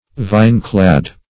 \Vine"-clad`\